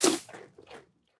minecraft / sounds / mob / goat / eat1.ogg
eat1.ogg